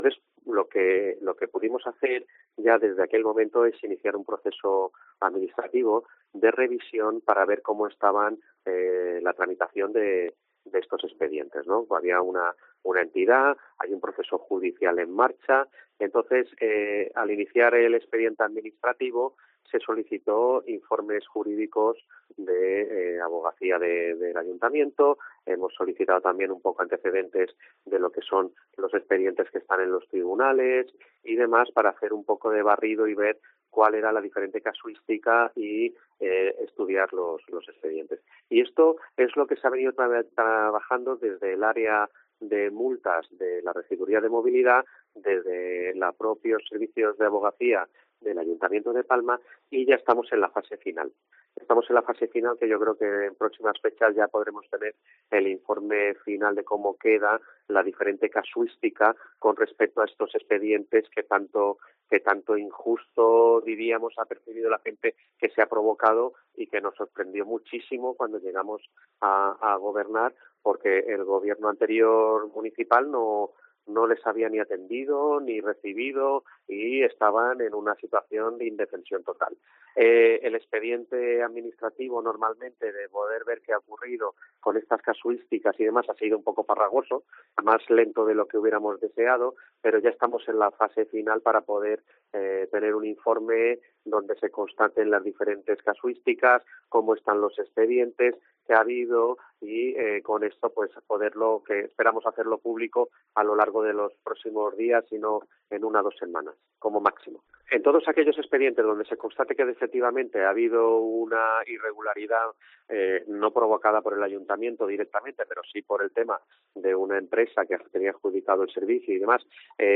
Antoni Deudero, concejal de movilidad de Palma